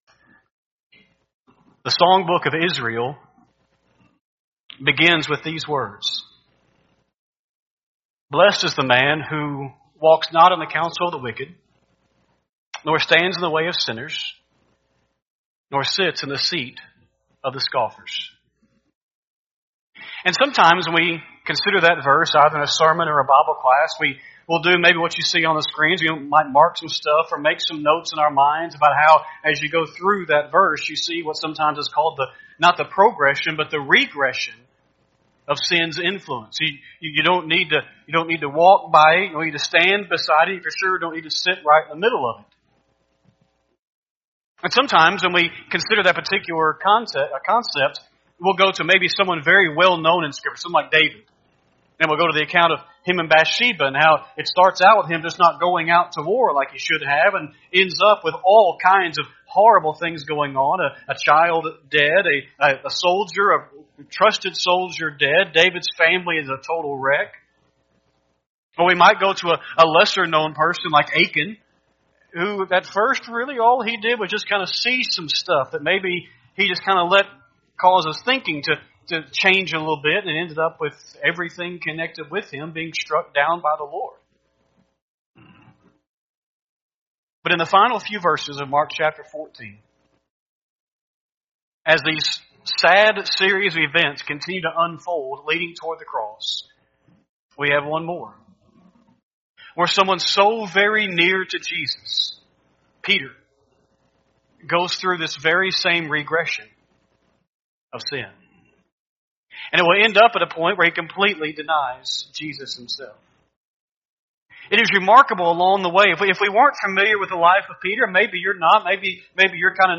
9-22-24-Sunday-AM-Sermon.mp3